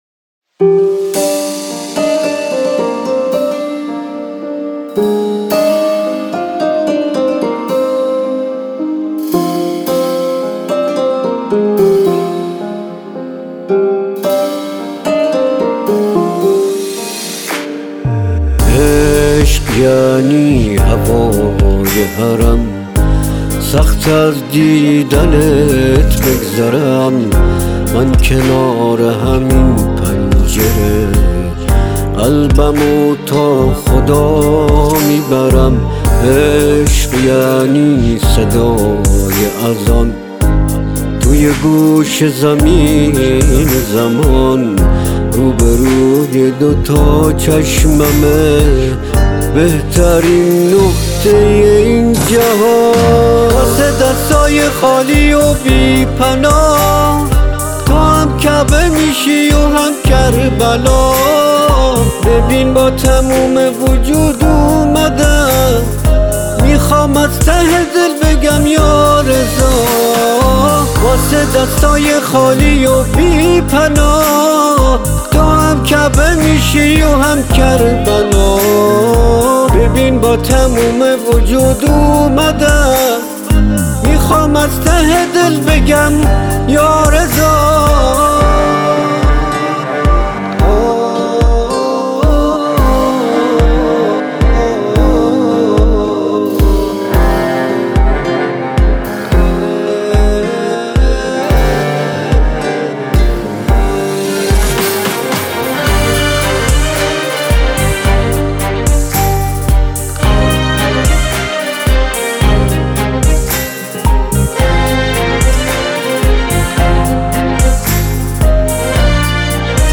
ترانه محلی زیبا و دلنشین